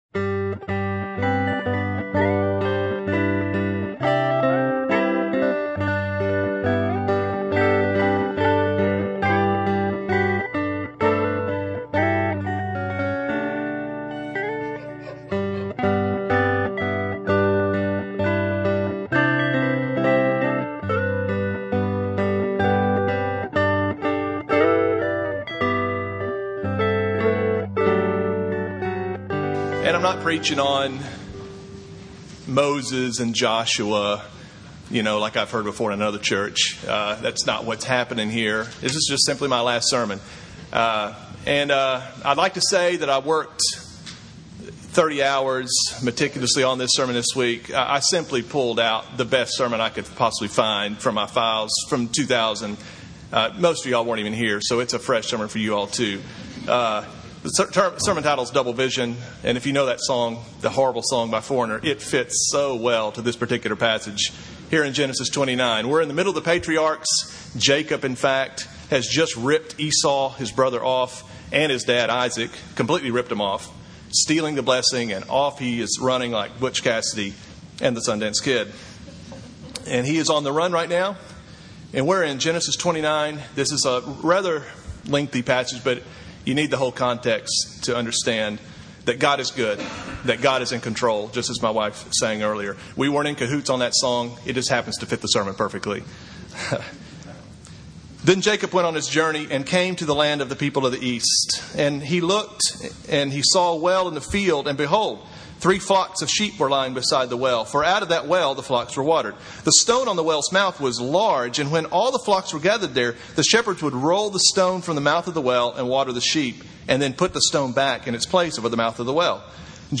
Sermon on Genesis 29:1-30 from June 18, 2006